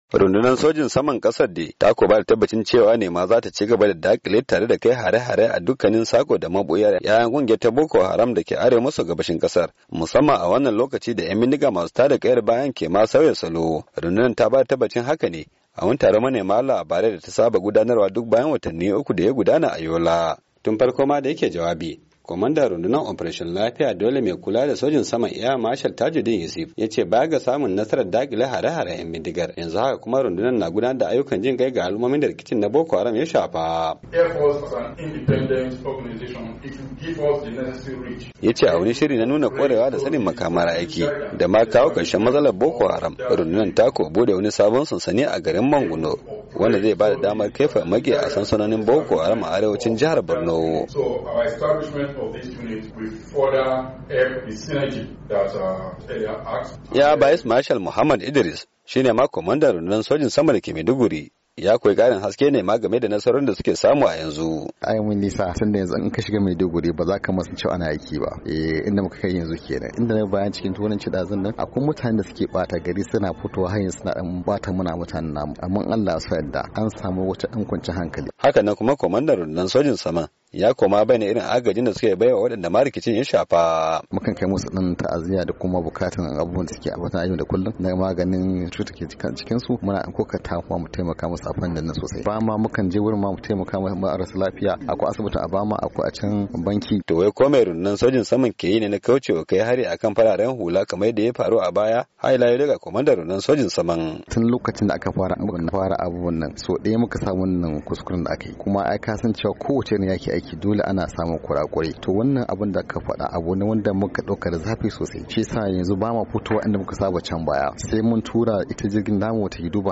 Rundunar ta bayyana haka ne a taron manema labarai da ta saba gudanarwa duk bayan wata uku wanda aka yi a birnin Yola, jihar Adamawa.